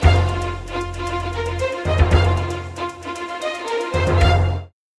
Звуки джинглов
• Качество: высокое